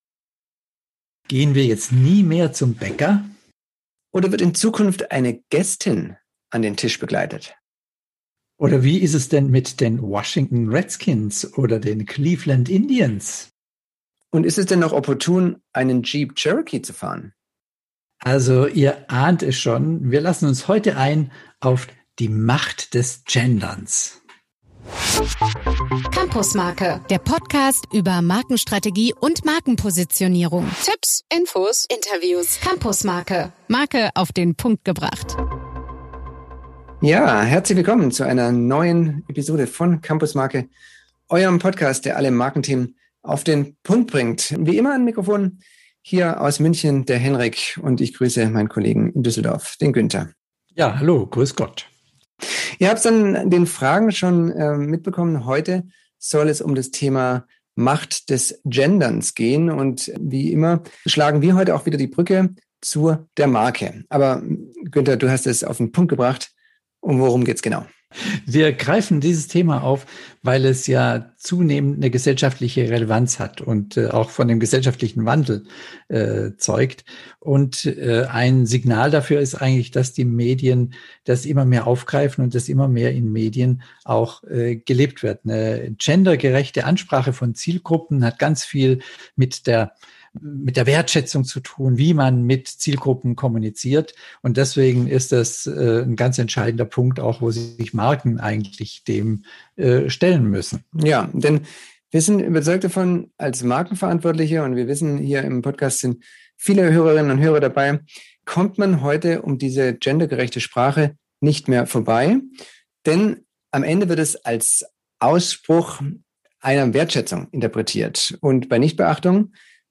Diese CampusMarke-Episode zeigt auf, wie Unternehmen und Organisationen mit ihren Produkten und Dienstleistungen den Erwartung gerecht werden können, gesellschaftliche Gruppen nach individuellen Bedürfnissen anzusprechen. Im Interview mit einer absoluten Expertin hinterfragen wir, wie ihr das Thema Gendern für eure Marke anpacken könnt und worauf es bei gendergerechter Sprache ankommt.